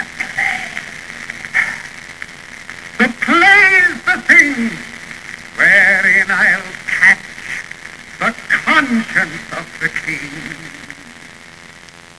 Three years later, he recorded the "Rogue and peasant slave" soliloquy for RCA Victor.